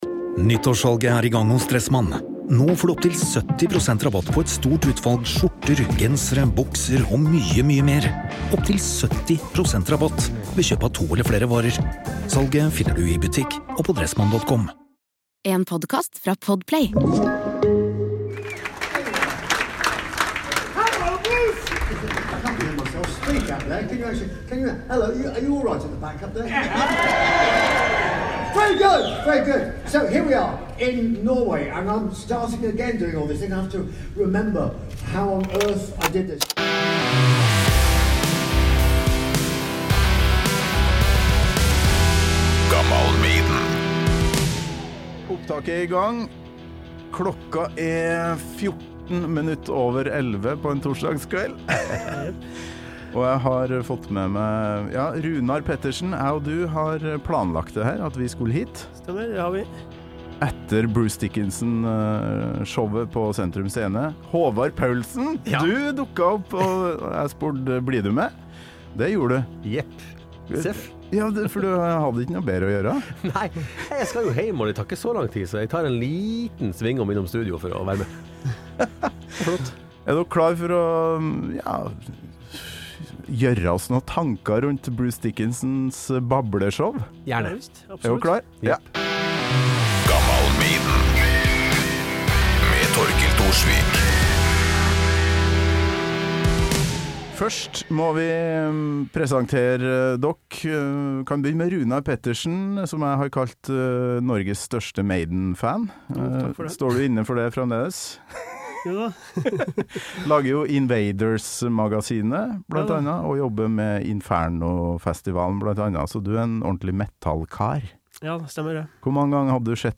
Jernbanetorget, Oslo.